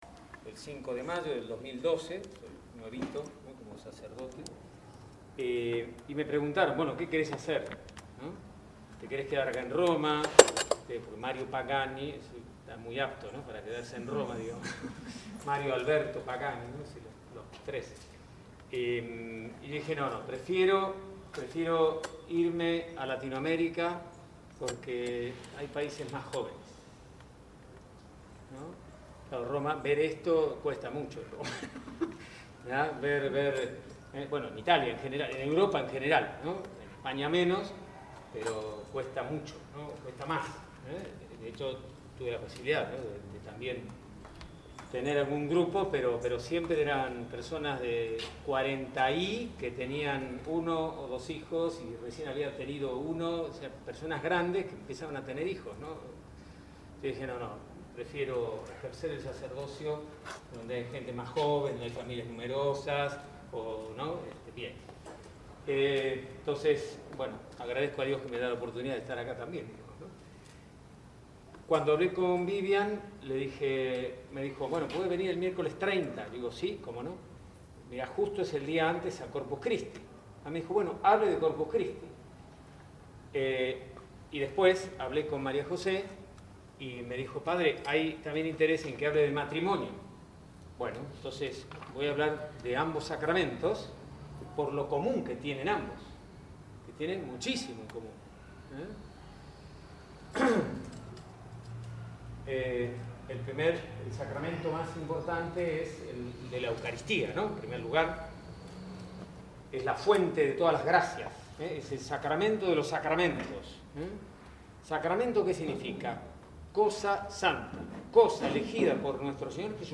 Los últimos miércoles de cada mes, nuestro grupo de Oración se divide en 3 eventos para tocar temas específicos destinados a Varones Solteros, Mujeres Solteras y Matrimonios. Esta vez compartimos el audio de la prédica